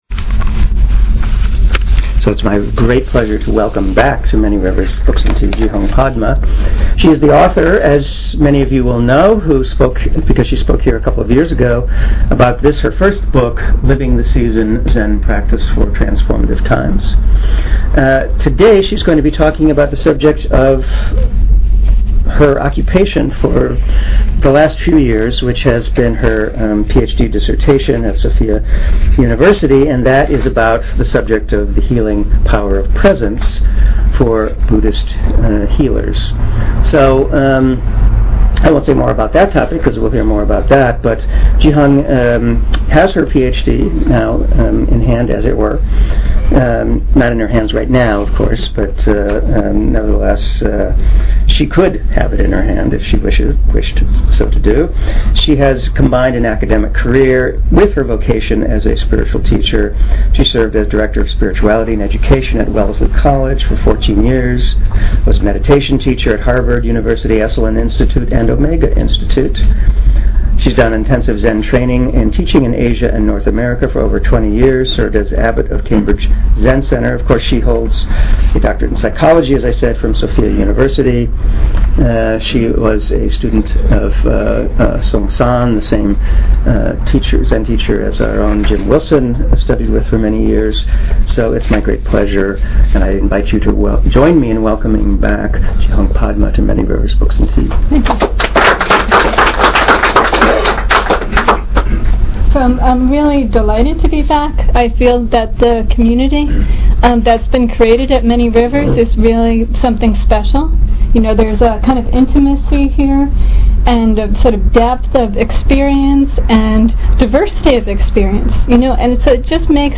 Archive of an event at Sonoma County's largest spiritual bookstore and premium loose leaf tea shop.
May this talk serve as a pathmarker on that journey, helping the community to discover the inner resources that support optimum physical, emotional and spiritual health.